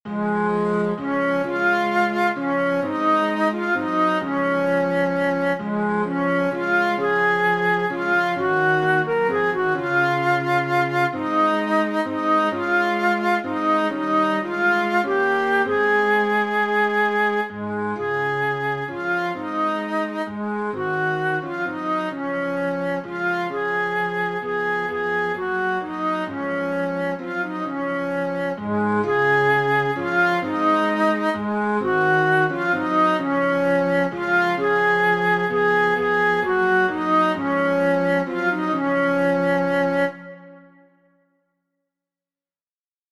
Resonet in Laudibus - Alto part